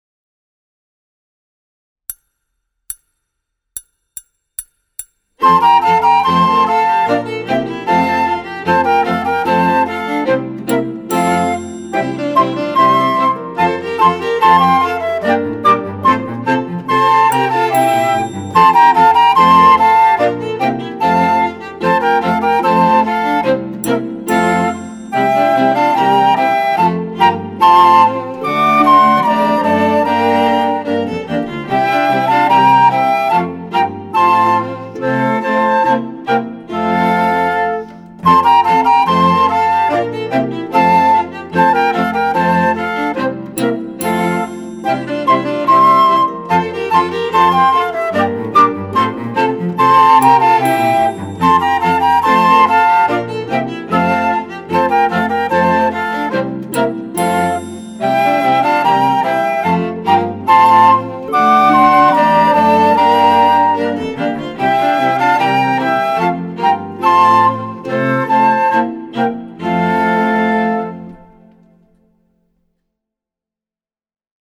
Gattung: für Querflöte und Klavier
Besetzung: Instrumentalnoten für Flöte